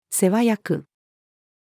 世話役-female.mp3